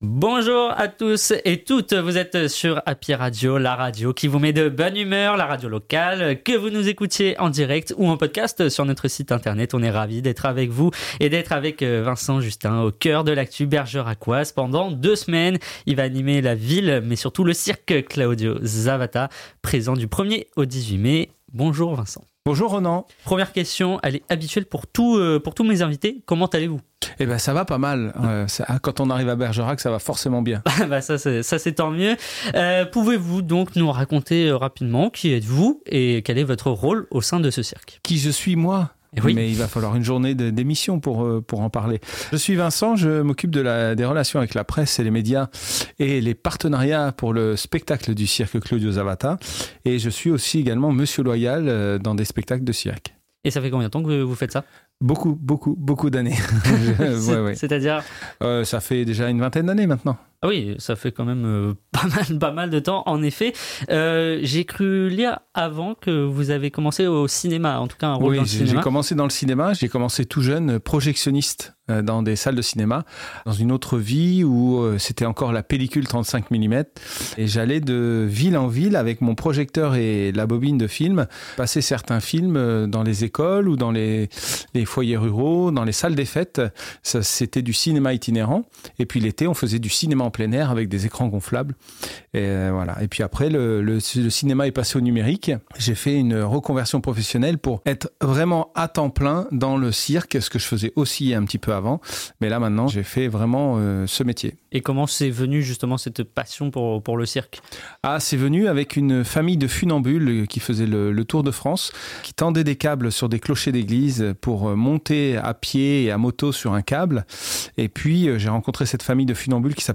LES INTERVIEWS HAPPY RADIO – CIRQUE CLAUDIO ZAVATTA